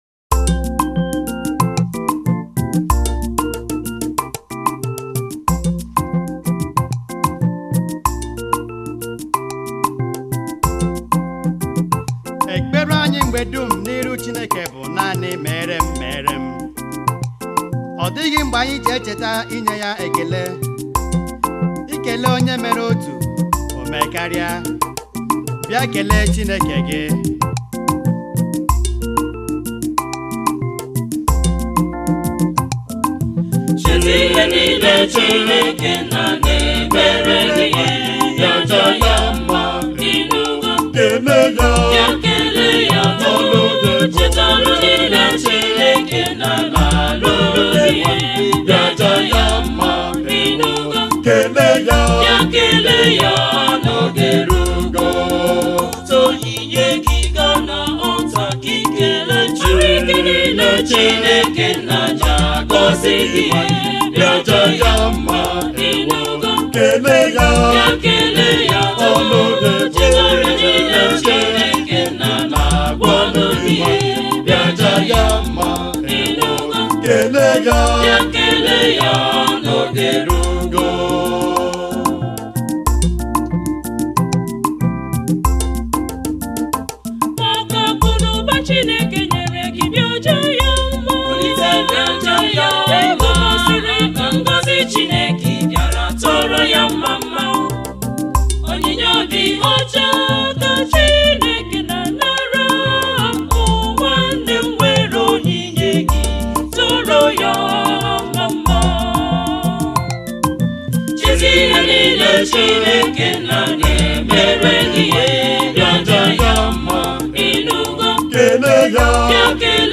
February 17, 2025 Publisher 01 Gospel 0